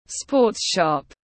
Sports shop /spɔːts ʃɒp/